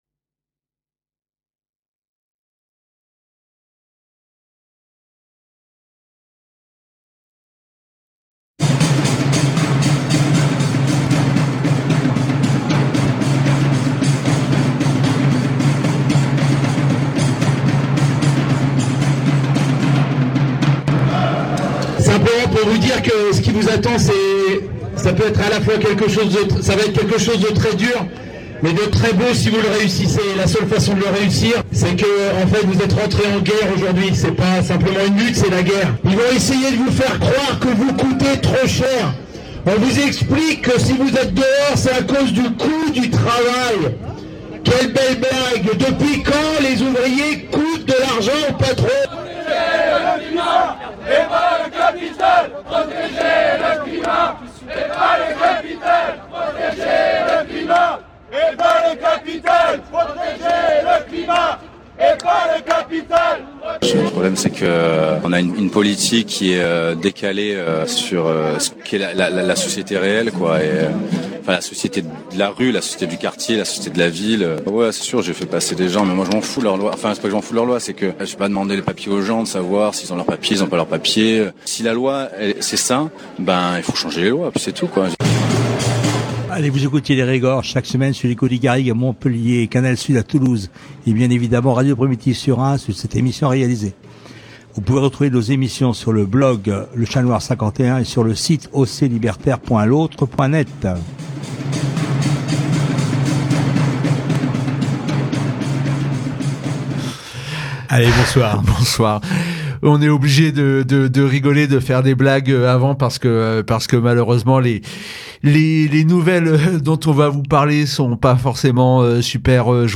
Ensuite, nous vous proposons d’entendre un extrait de l’intervention du collectif des blessés de Ste Soline lors de l’après-midi du 10 janvier à Poitiers.